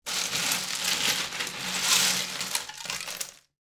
Ice Cubes Sound Effect 4 Soda / beer can picked up from ice
ice-cubes-4.wav